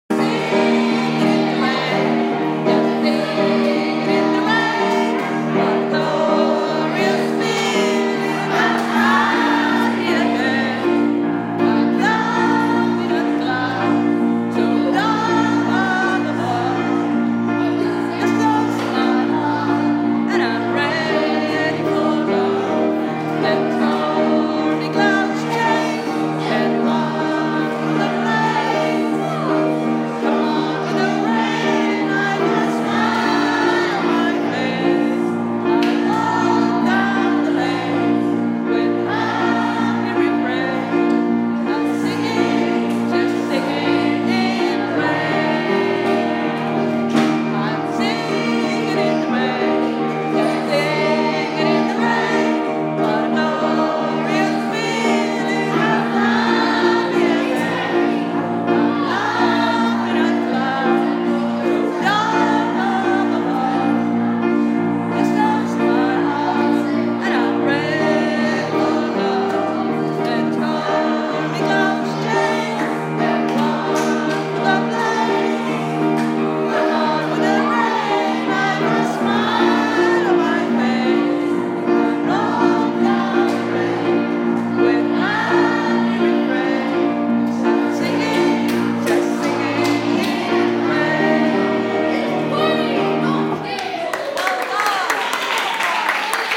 Turney School Choir